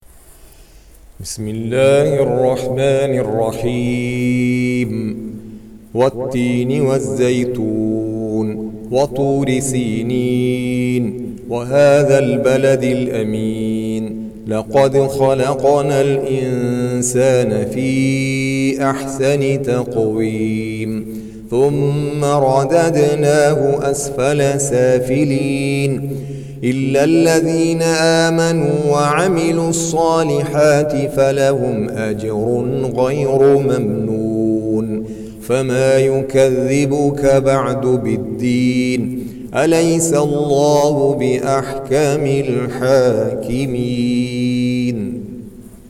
Surah Sequence تتابع السورة Download Surah حمّل السورة Reciting Murattalah Audio for 95. Surah At-Tin سورة التين N.B *Surah Includes Al-Basmalah Reciters Sequents تتابع التلاوات Reciters Repeats تكرار التلاوات